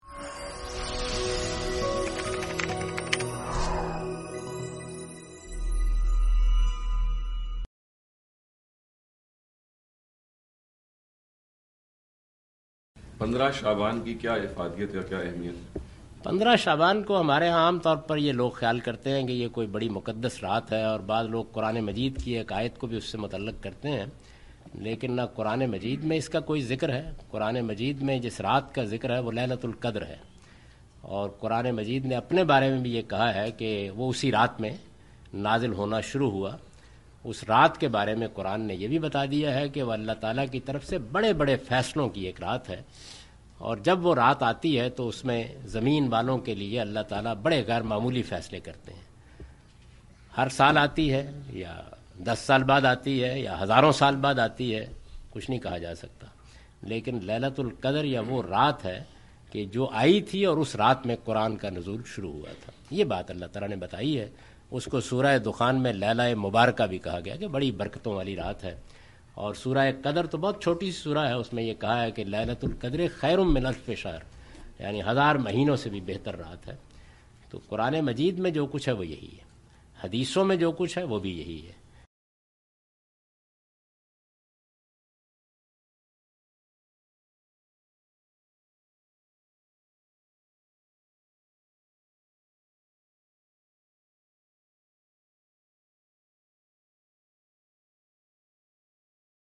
In this video Javed Ahmad Ghamidi answer the question about "Significance of Shabb-e-Barat (15th Shabaan)" asked at Adria Hotel, Bayside, Queens, New York on May 30,2015.